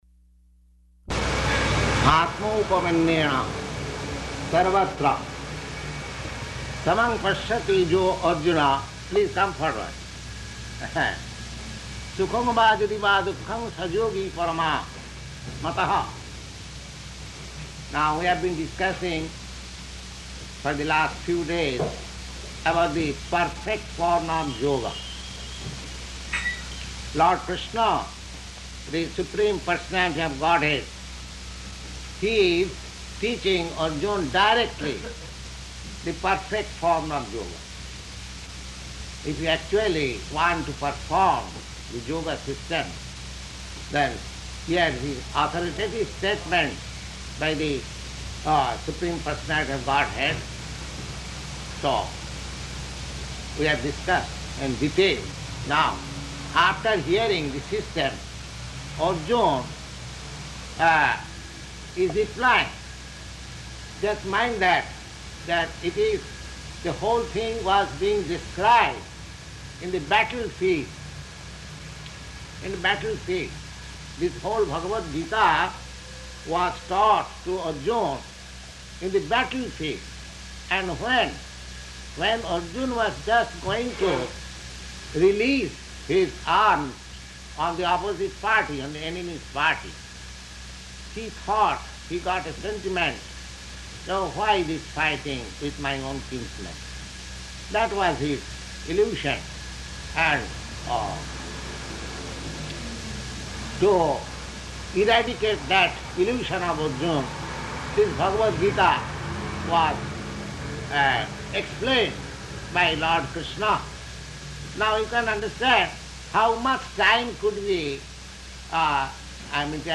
Bhagavad-gītā 6.32–40 --:-- --:-- Type: Bhagavad-gita Dated: September 16th 1966 Location: New York Audio file: 660916BG-NEW_YORK.mp3 Prabhupāda: ātmaupamyena sarvatra samaṁ paśyati yo 'rjuna...